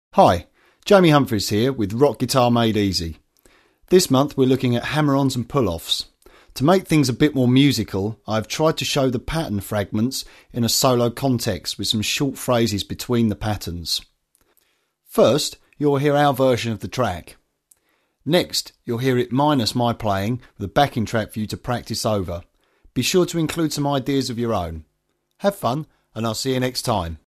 Pentatonic hammer-on and pull-off sequences Exercise